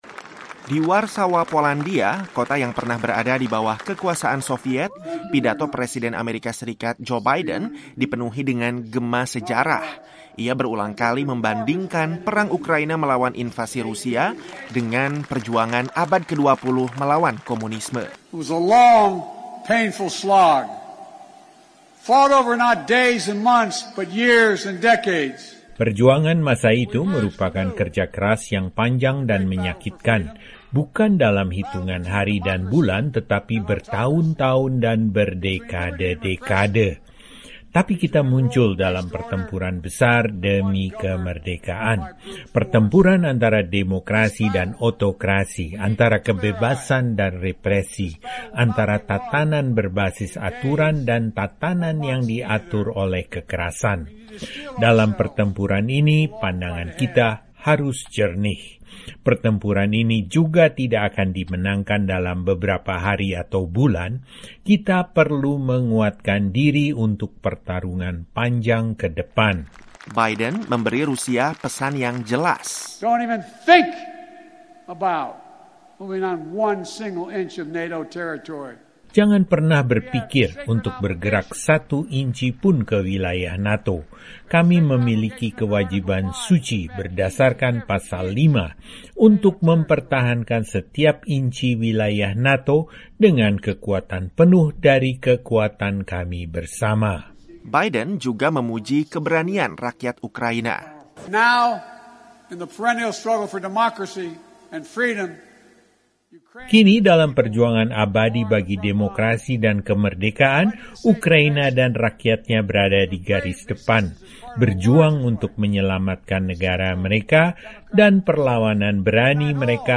Pidato Berapi-api Biden: Putin ‘Tak Boleh Tetap Berkuasa’
Dalam pidato berapi-api di Polandia (26/3), Presiden AS Joe Biden mengatakan Presiden Rusia Vladimir Putin “tidak boleh tetap berkuasa” dan bersumpah akan membela setiap inci wilayah NATO. Pidato itu disampaikan setelah seminggu penuh berdiplomasi dengan NATO, G-7 dan sekutu Eropa megenai Ukraina.